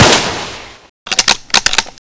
kar98k_shoot_sil.ogg